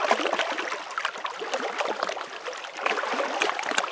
eau_calme.wav